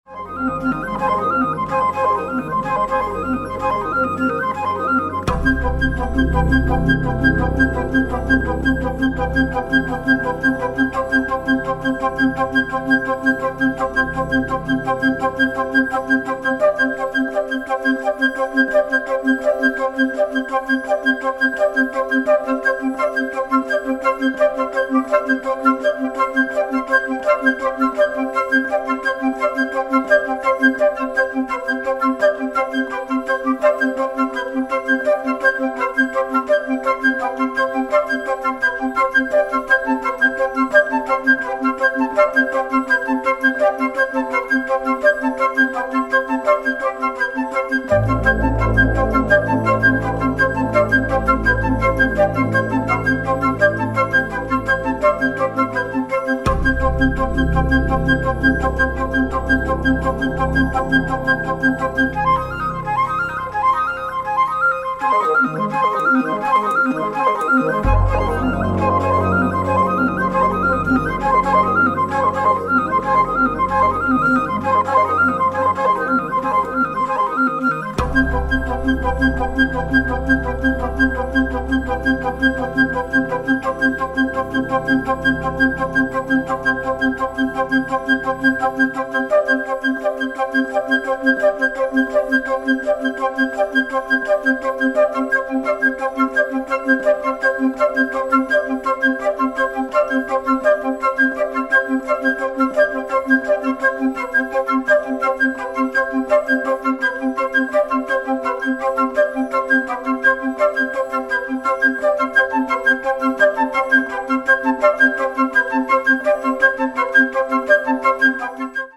minimalisms
flutist